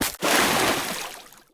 Sfx_creature_babypenguin_jump_to_water_03.ogg